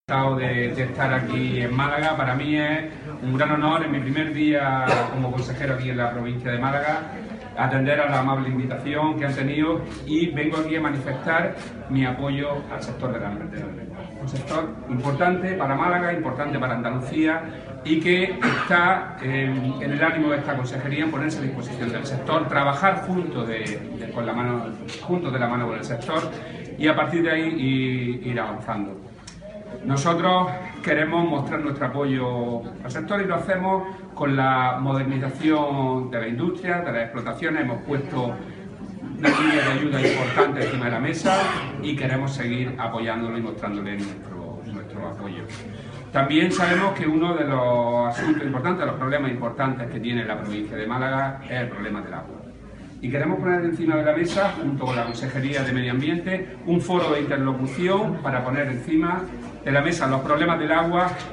Rodrigo Sánchez participa en el 40 aniversario de Almendrera del Sur
Declaraciones de Rodrigo Sánchez sobre apoyo al sector